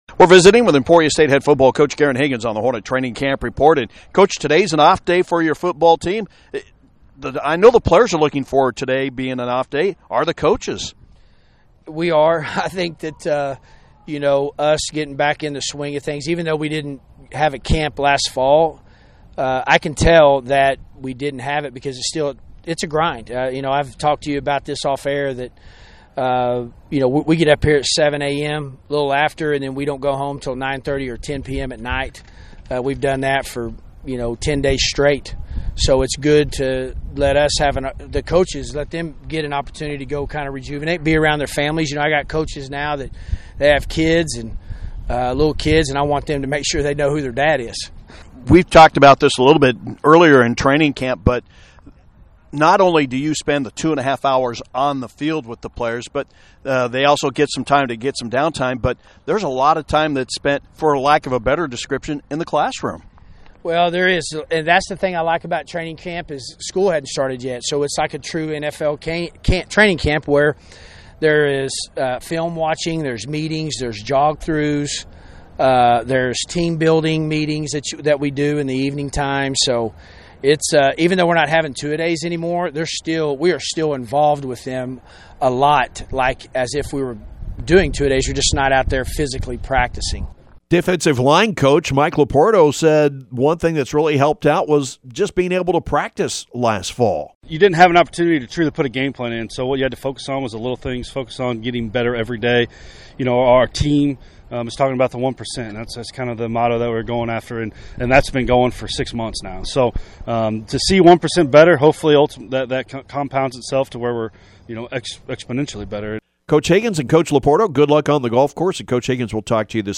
Emporia State football training camp report